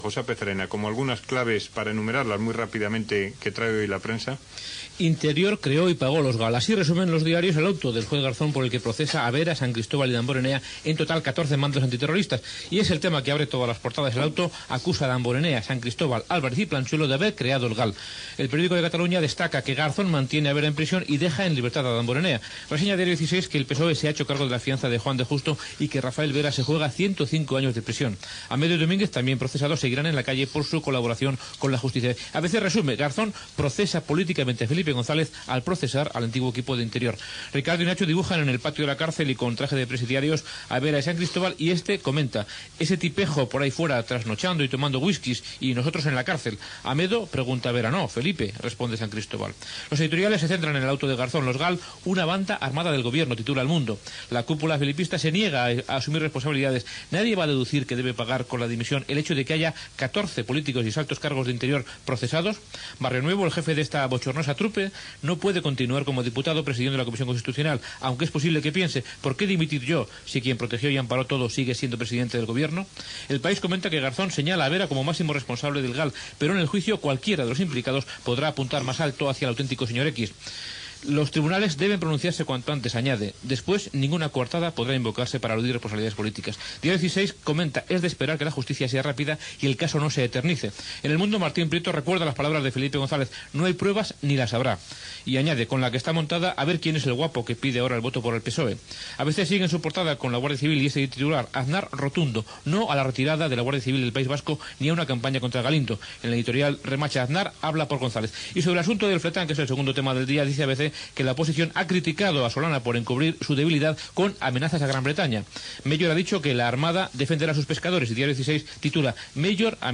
Resum informatiu.
Connexió amb la unitat mòbil de la COPE.